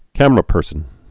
(kămər-ə-pûrsən, kămrə-)